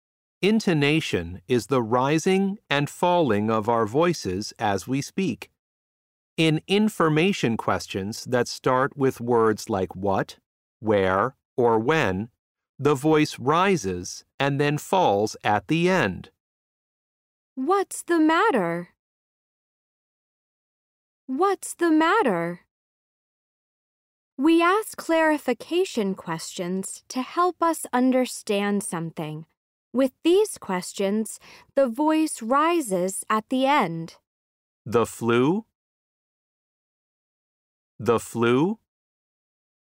SO1- Unit 6- Lesson 2 (Intonation).mp3